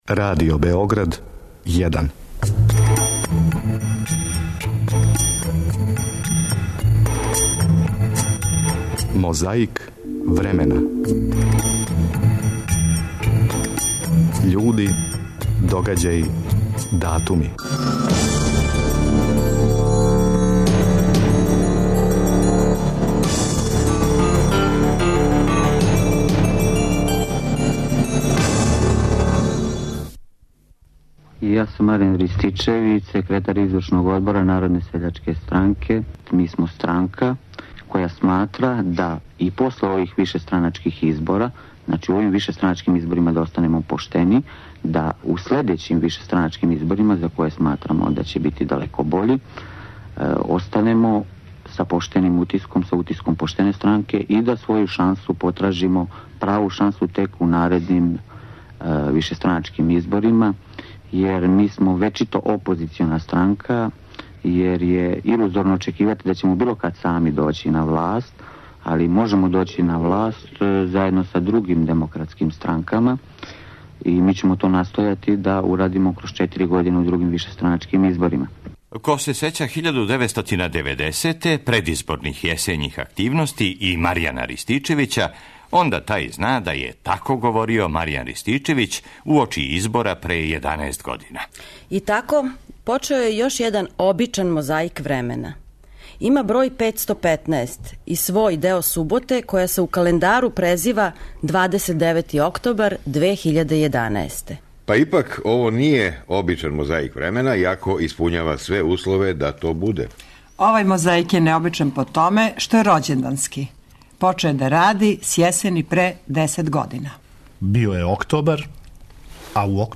Чућемо један Ристичевићев говор уочи тих првих вишестраначких избора, као и инсерте из гостовања Драшковића на Радио Београду исте године.